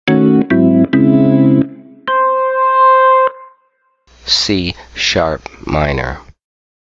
This contextual based ear training method teaches note recognition within a key center using the sound of a Organ.
06_CSharp_mMajor7_KN-C3_Organ_L3.mp3